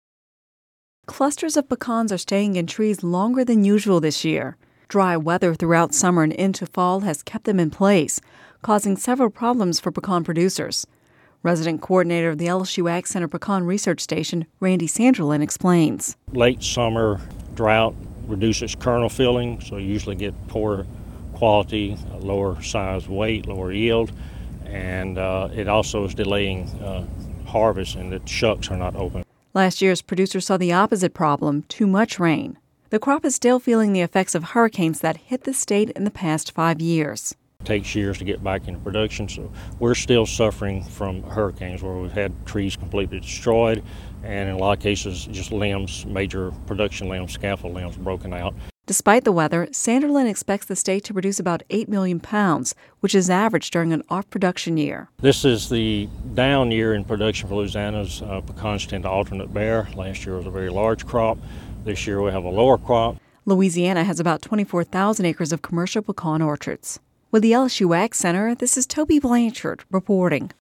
(Radio News 11/08/10) Clusters of pecans stayed in trees longer than usual this year. Dry weather throughout summer and into fall kept them in place, causing several problems for pecan producers.